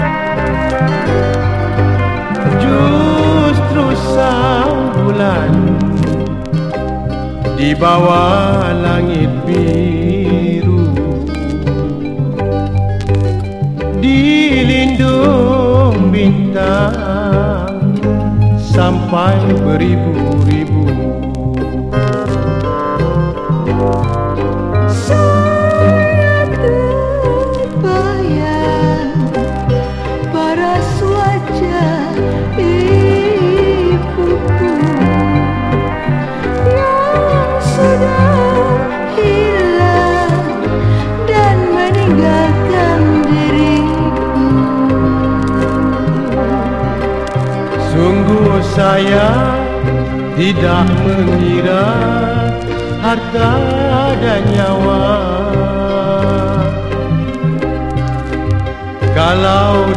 KERONCHONG (MALAYSIA)
郷愁と情緒と美しさを湛えたマレーシア・クロンチョン！